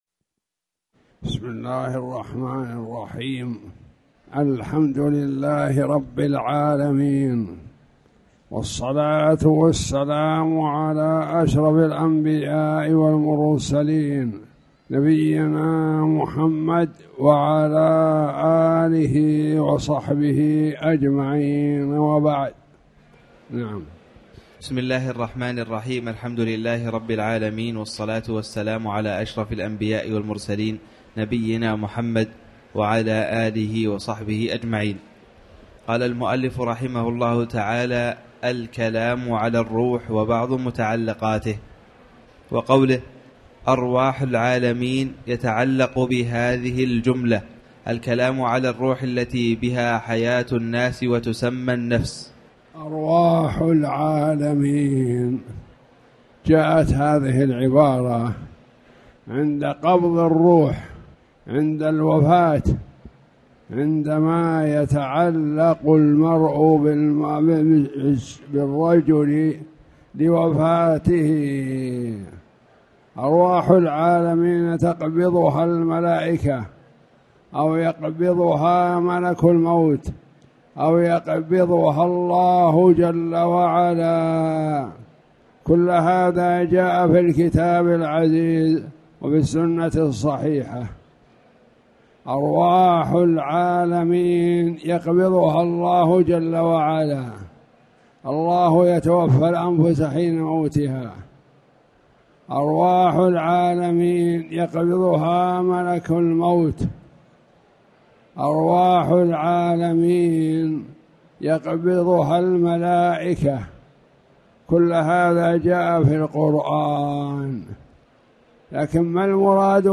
تاريخ النشر ٩ ذو القعدة ١٤٣٨ هـ المكان: المسجد الحرام الشيخ